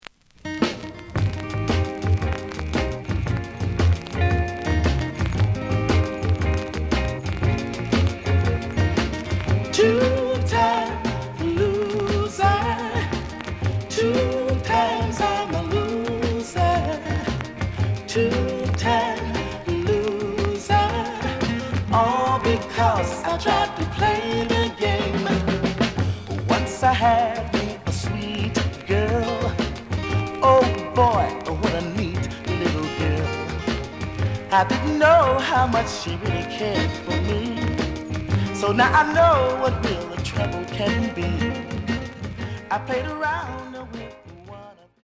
The audio sample is recorded from the actual item.
●Format: 7 inch
●Genre: Soul, 60's Soul
Slight edge warp.